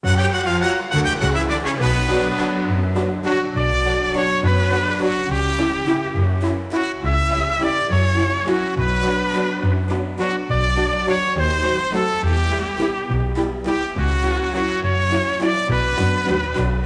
music-loop-3.wav